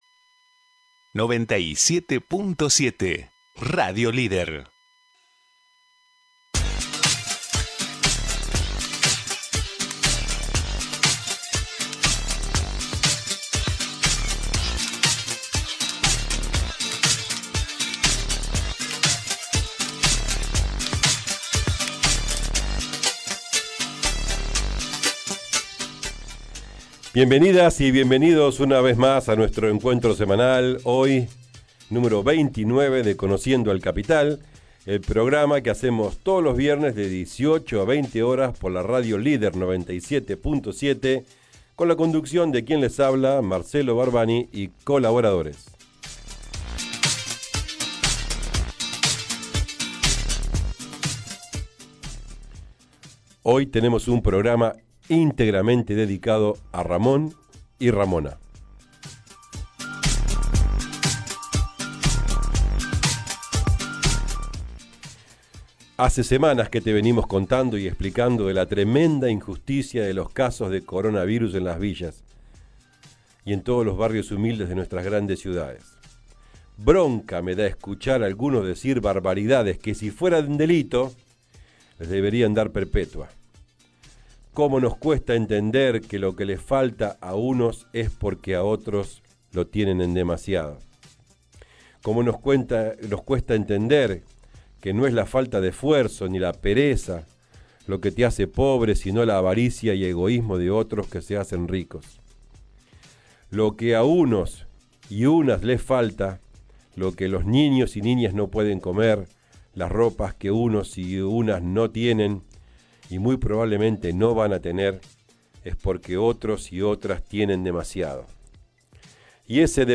Conociendo al Capital – Editorial del 22 de Mayo de 2020